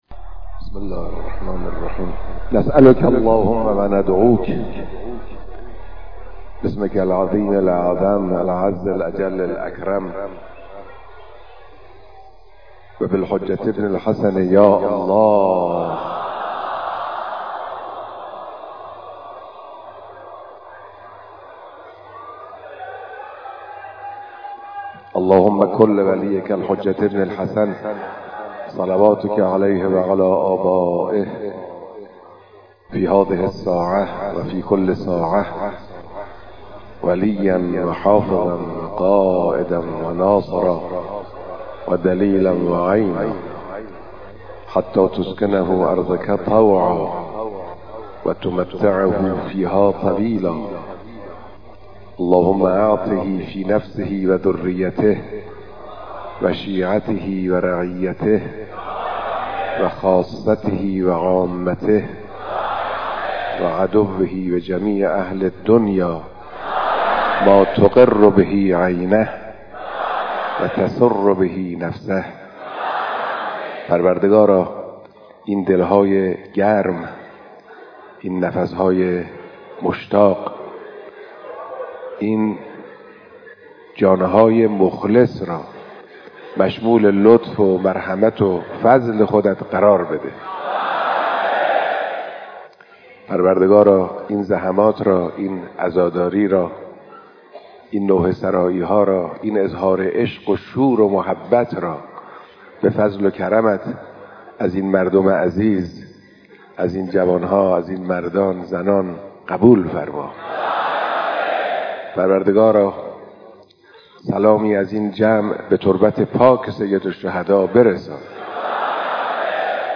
دعای رهبر انقلاب در پایان مراسم عزاداری اربعین